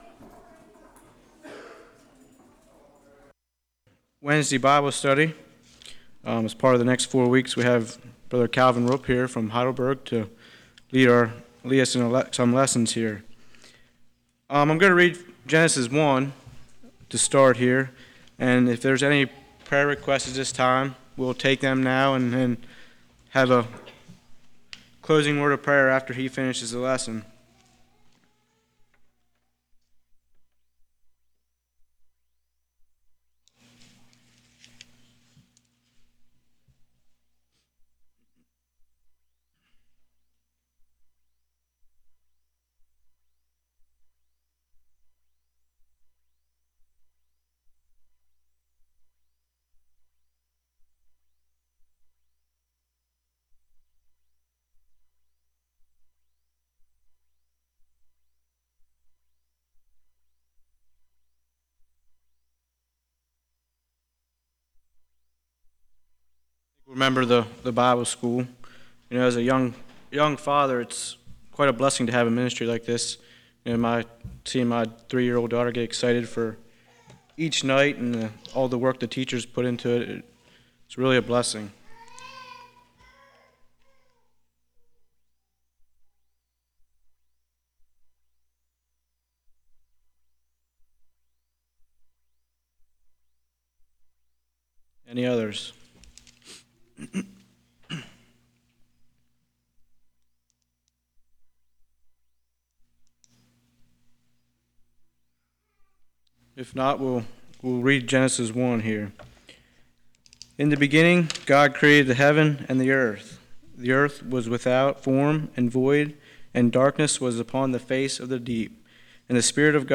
Passage: Genesis 1:1-31 Service Type: Winter Bible Study